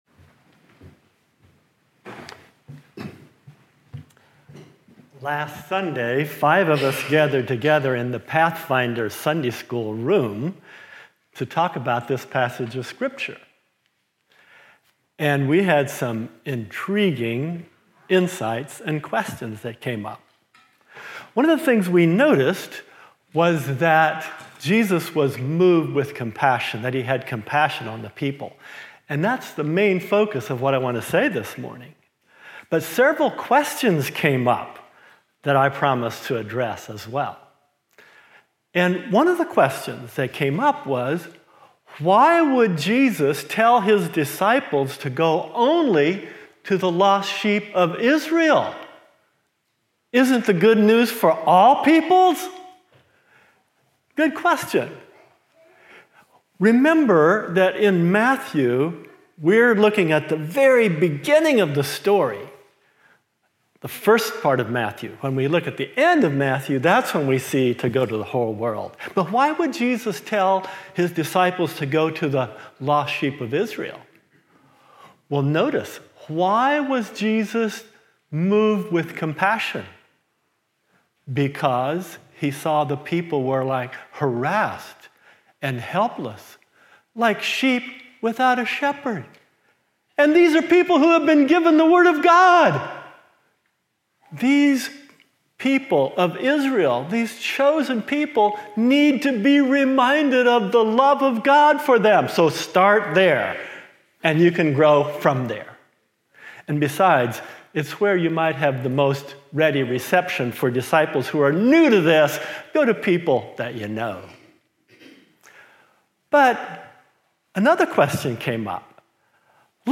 II Peter 1:3-8 and Ecclesiastes 3:1-14 Order of worship/bulletin Youtube video recording Sermon audio recording.